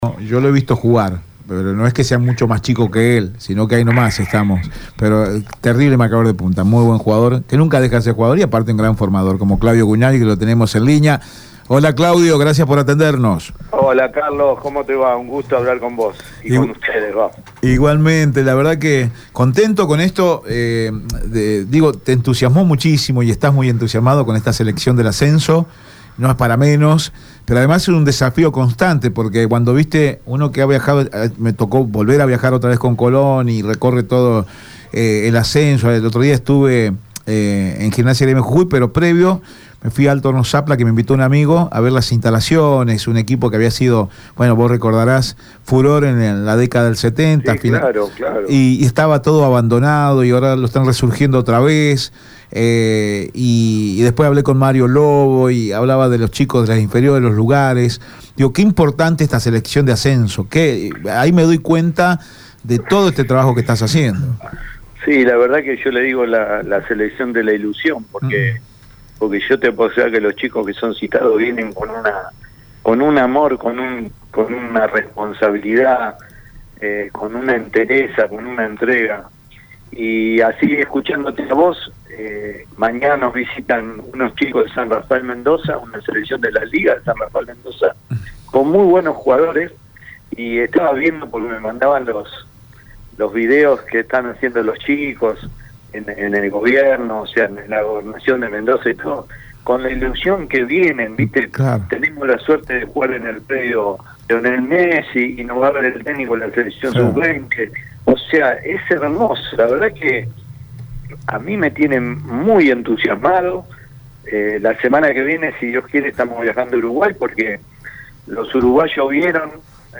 dialogó con EME Deportivo